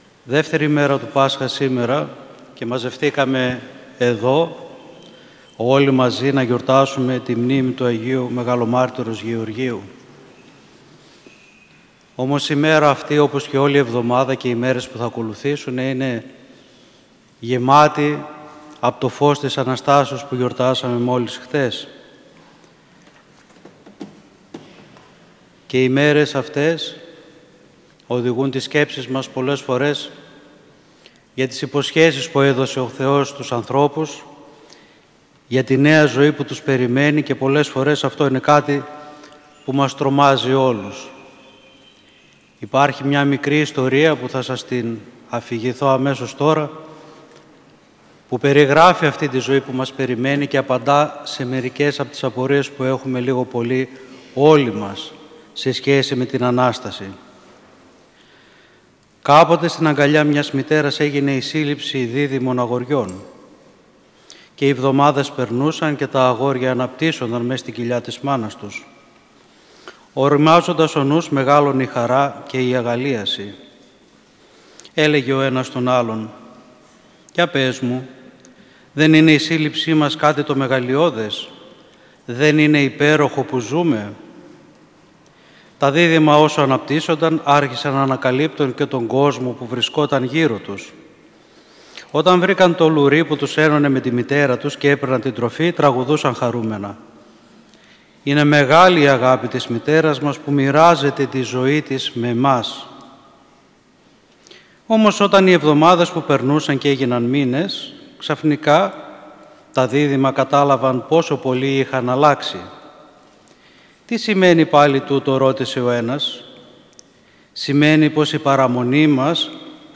Την κυριώνυμο ημέρα της εορτής του Αγίου Γεωργίου του Τροπαιοφόρου και Μεγαλομάρτυρος, Δευτέρα 3 Μαΐου 2021, ο Σεβασμιώτατος Μητροπολίτης Νεαπόλεως και Σταυρουπόλεως κ. Βαρνάβας ιερούργησε στον Πανηγυρίζοντα Ιερό Μητροπολιτικό Ναό του Αγίου Γεωργίου στη Νεάπολη.
Κήρυγμα